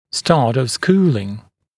[stɑːt əv ‘skuːlɪŋ][ста:т ов ‘ску:лин]начало занятий в школе